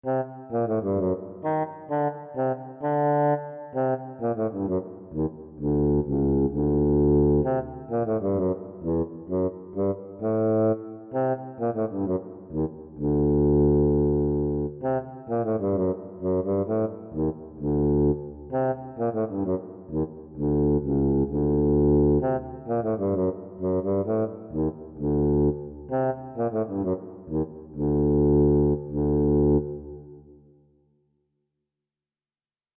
Música ambiental
ambiente cuento melodía música sintonía